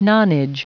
Prononciation du mot nonage en anglais (fichier audio)
Prononciation du mot : nonage